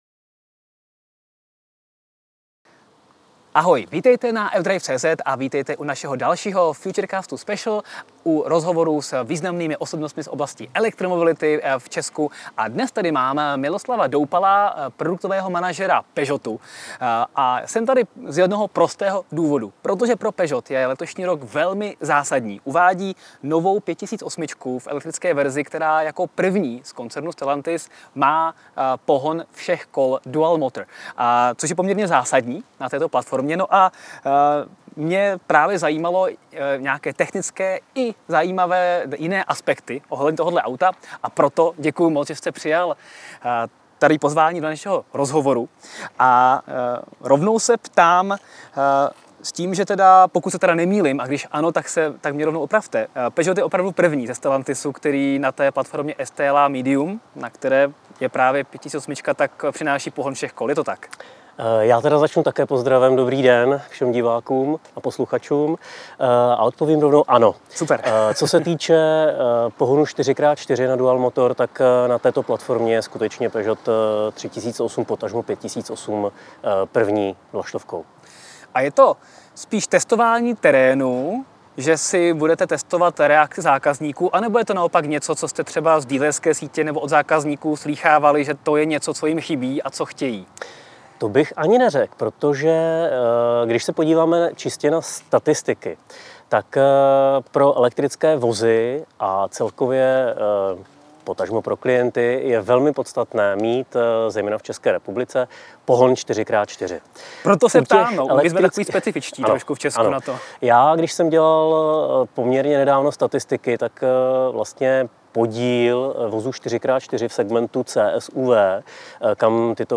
Rozhovor Futurecast Special